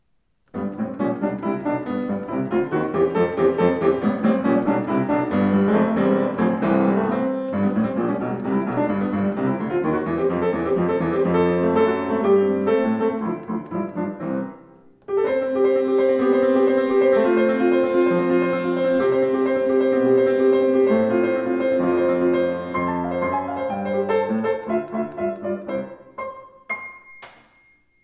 Klaviere